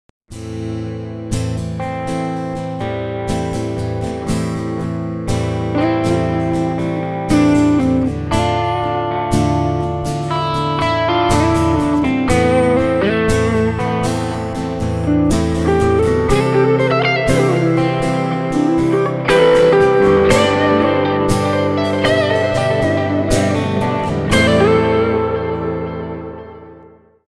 The soundclips are organized by amp type and feature a wide variety of tonal possibilities that are possible with our amps.
M1 Soft Drive Slight overdrive Fender Stratocaster   644Kb
M1_SOFT_DRIVE.mp3